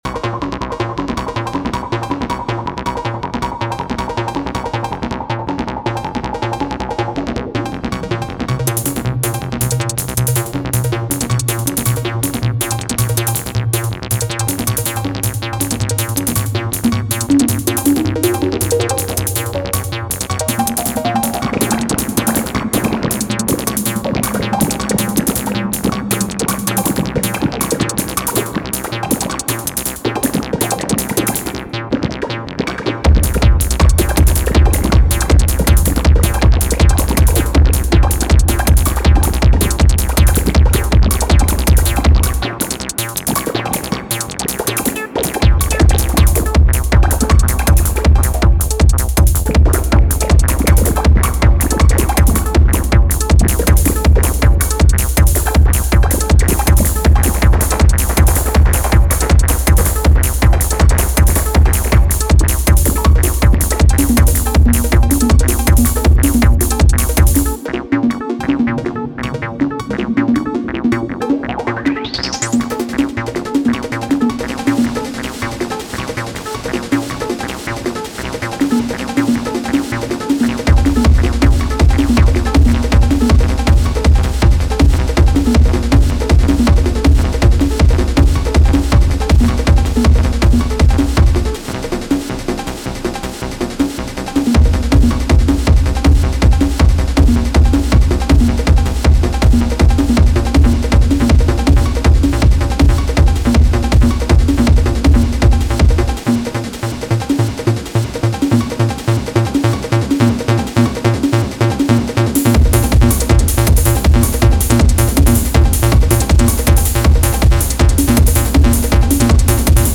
Sugar Bytes Aparillo/Drumcomputer - Ableton’s Echo- Arturia’s Tape Mello-Fi